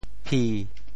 潮州拼音“pi7”的详细信息
潮州府城POJ phī 国际音标 [p]